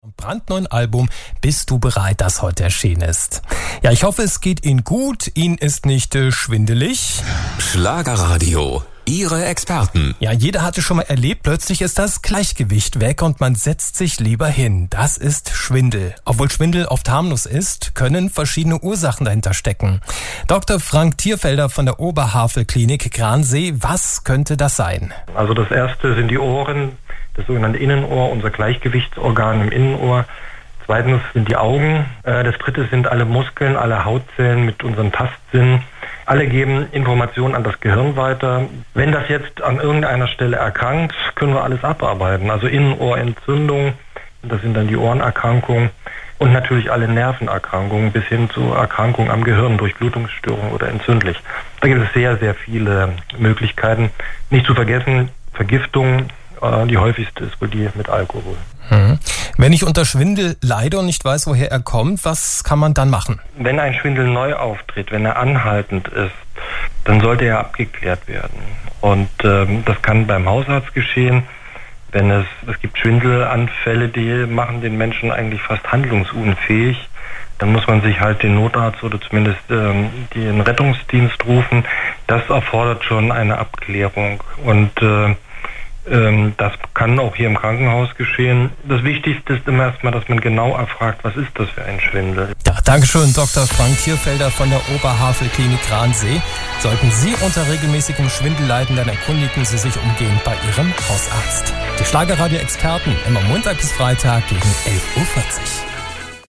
im Interview bei Schlagerradio.